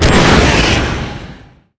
concmissilefire5.wav